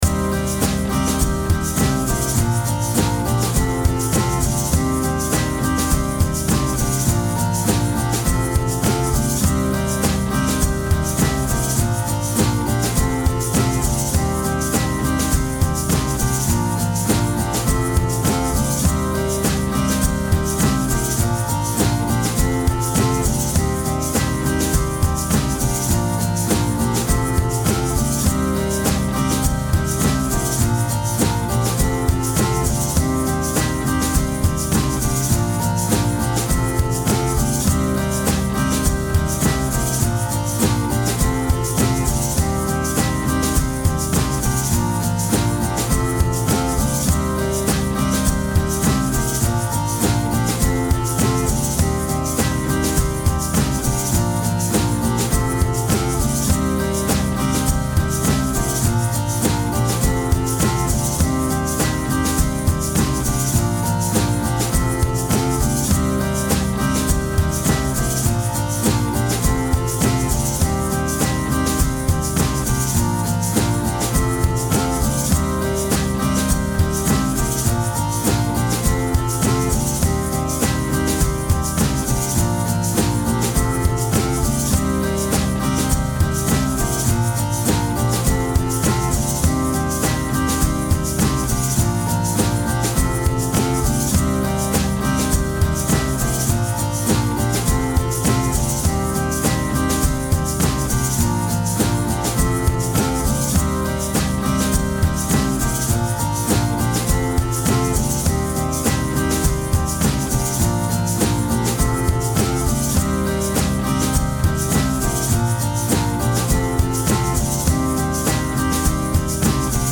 Home > Music > Blues > Bright > Laid Back > Chasing